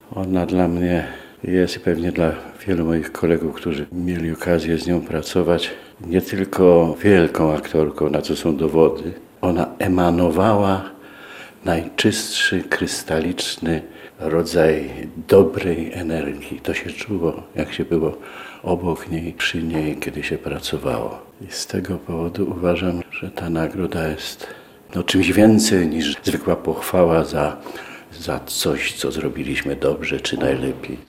Janusz Gajos powiedział Radiu Lublin, że dla niego to szczególna nagroda, bo wiąże się z postacią wybitnej aktorki, jaką była Szaflarska: – Ona dla mnie, i pewnie dla wielu moich kolegów, którzy mieli okazję z nią pracować, jest nie tylko wielką aktorką, na co są dowody.